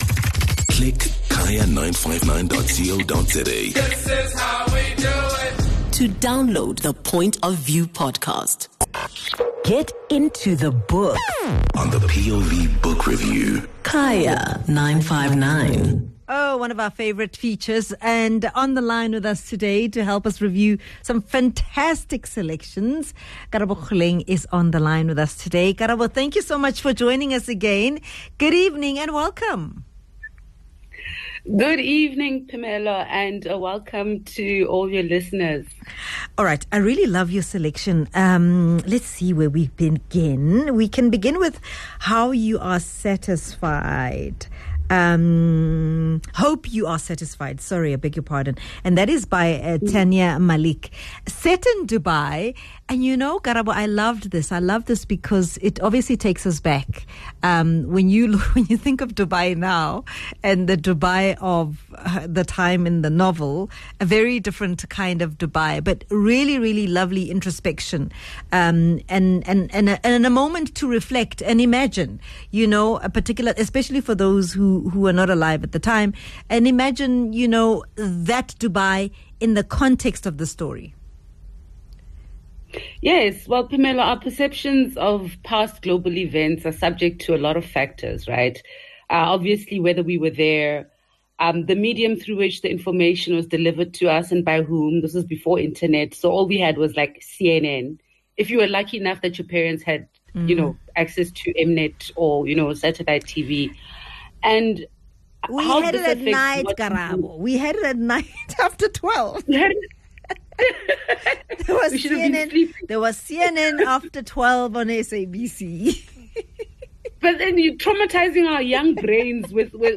a rich literary conversation